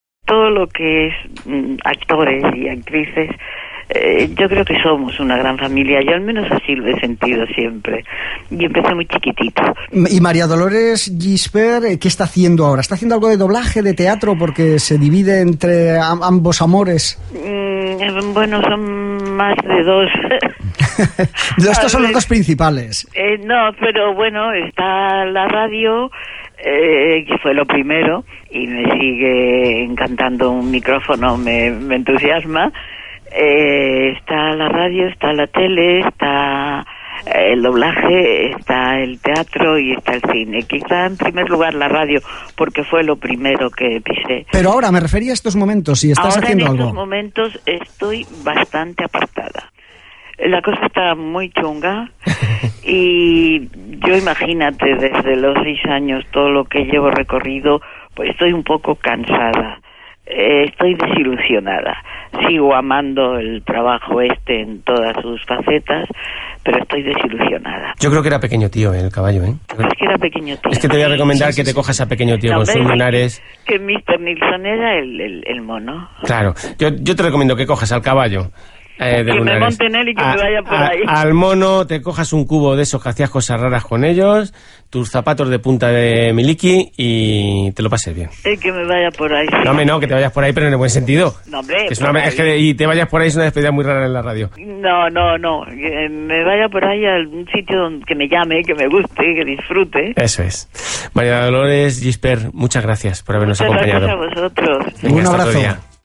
Esto me suena: entrevistada - RNE, 2010